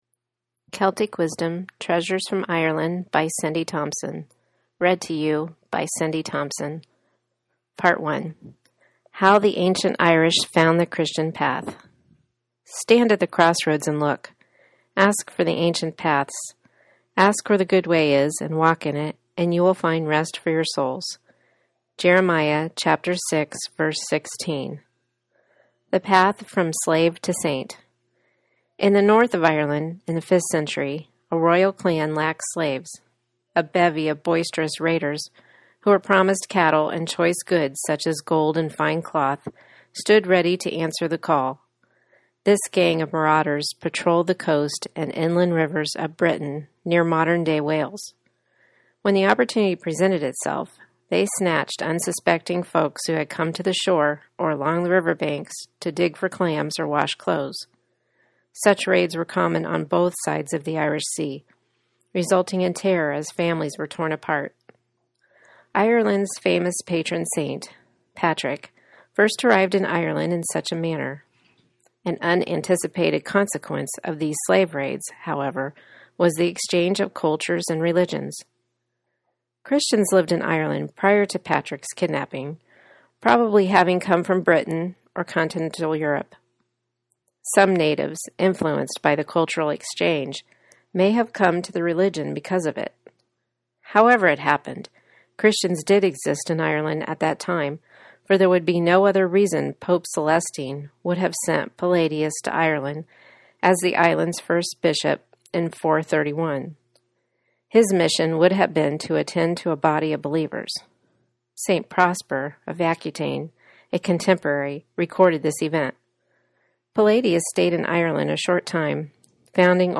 There are Irish words in there, and the book was published in UK English, so some things are not the way I would usually say them.
It’s not perfect, but for those of you who like listening to podcasts, you might like it.